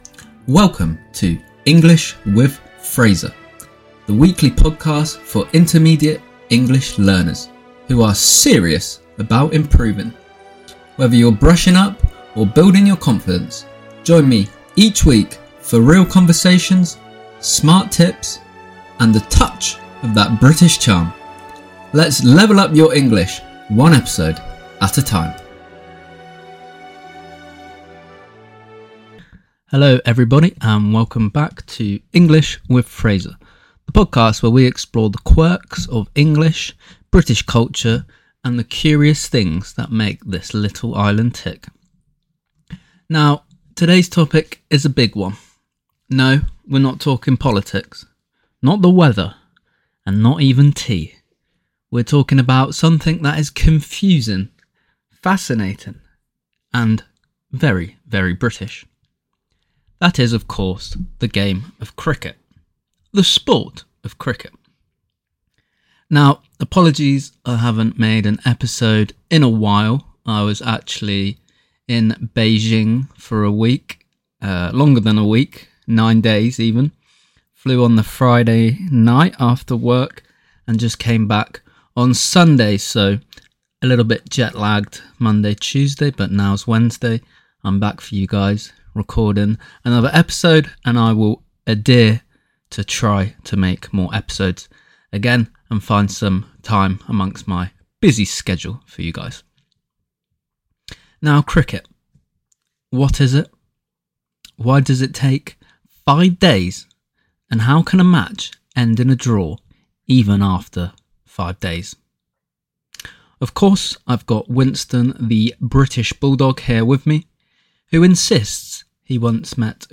British English.